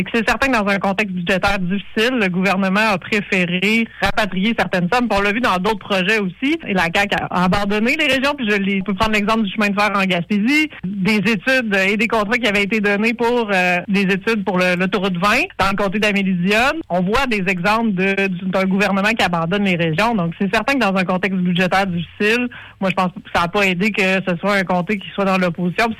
Au lendemain du dépôt d’un projet de loi favorisant l’immigration en région, madame Blanchette-Vézina était en entrevue sur nos ondes vendredi dernier.